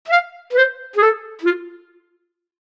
brass-fail-11.ogg